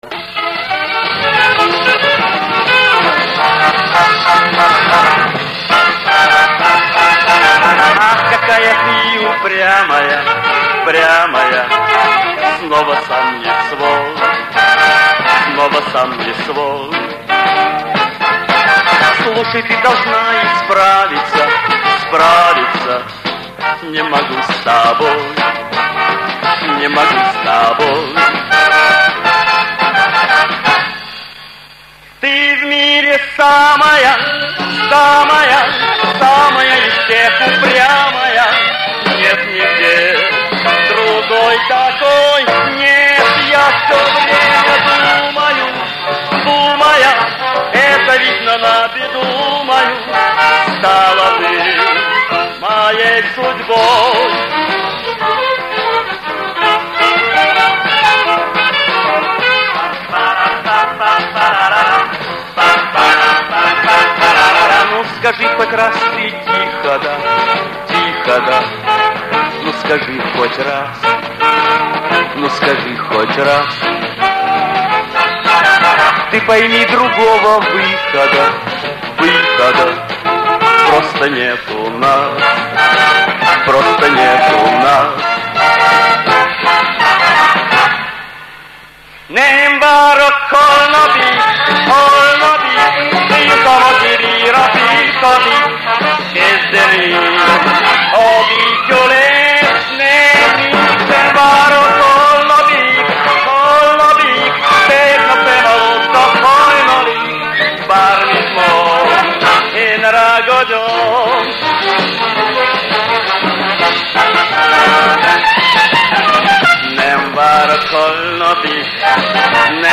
оркестровая версия